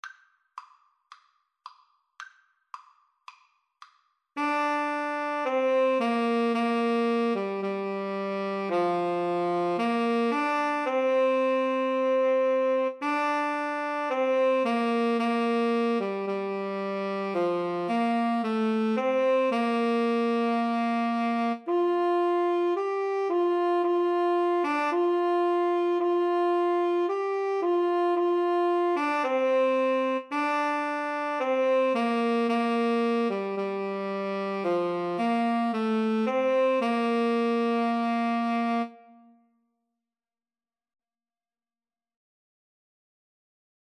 Tenor Sax Duet  (View more Easy Tenor Sax Duet Music)
Classical (View more Classical Tenor Sax Duet Music)